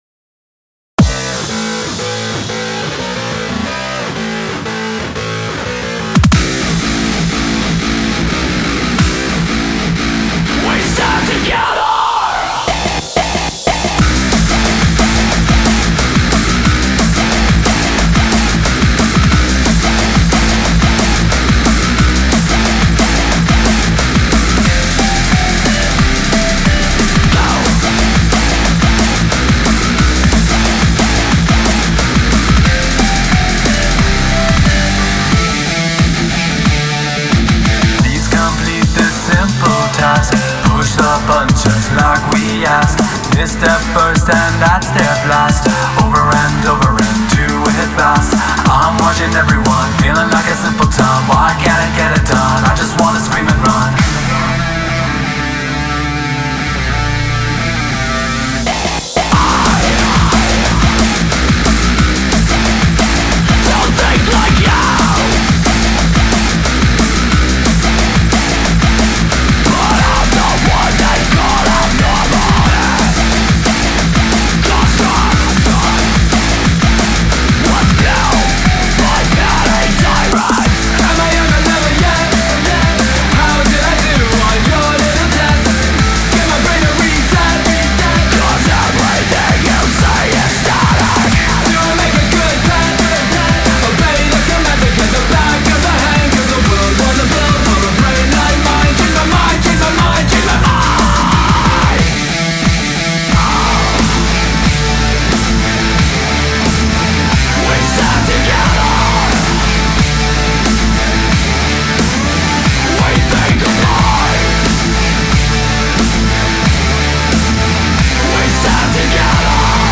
BPM180-180
Audio QualityPerfect (High Quality)
Full Length Song (not arcade length cut)